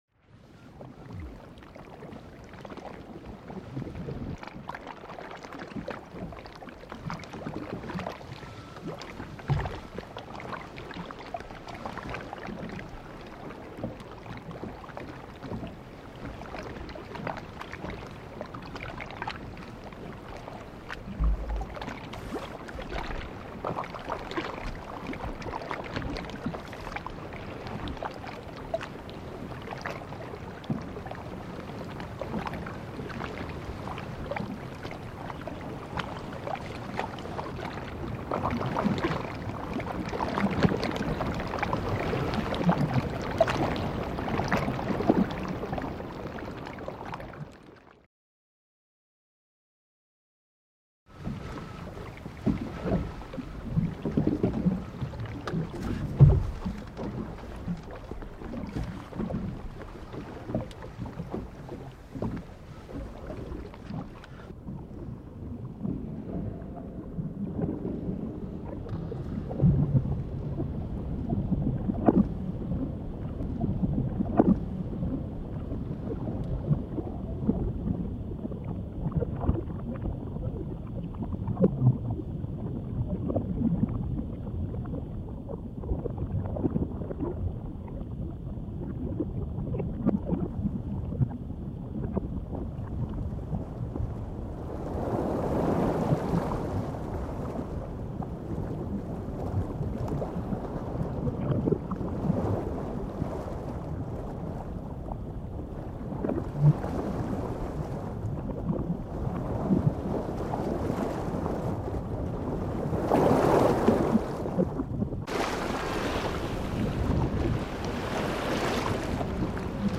جلوه های صوتی
دانلود صدای وال 19 از ساعد نیوز با لینک مستقیم و کیفیت بالا